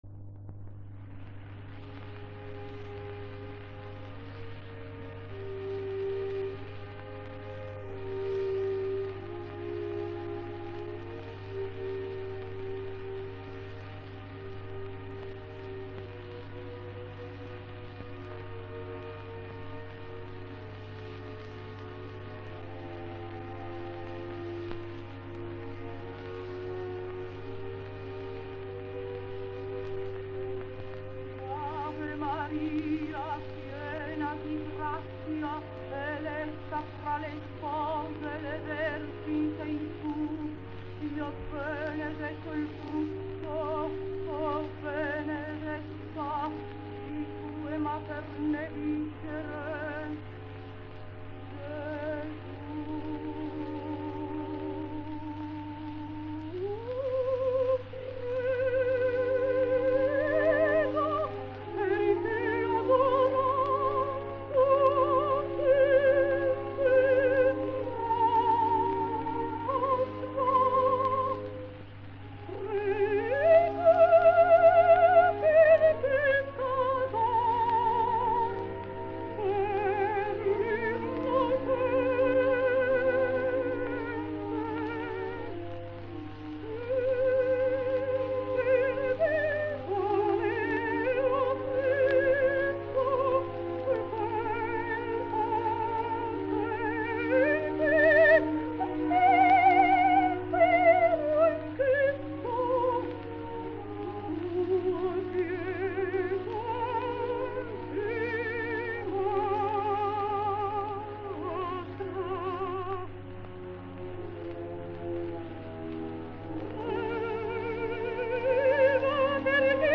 La vera lezione di interpretazione, di canto come espressione dell’animo e del sentire arriva invece da due soprani cosiddetti di forza dei primi vent’anni del XX secolo ovvero la Poli Randaccio e la Mazzoleni. Credo che queste due prime donne insieme ad almeno altre quattro o cinque (Burzio, Russ, Pinto, Boninsegna e se avesse inciso Cecilia Gagliardi) rappresentino la testimonianza più alta dell’arte del canto italiana nelle voci drammatiche e ciò non di meno l’abitudine all’accento poderoso e solenne, agli acuti di devastante ampiezza cede il passo  nella preghiera ultima di Desdemona alla castigatezza d’accento, al legato, al suono dolce ed all’emissione morbida e controllata. Esempio di grandissimo canto, esempio di come talvolta circolino ingiustificati luoghi comuni e communis opinio, meritevoli di sola smentita o revisione critica.
Tina Poli Randaccio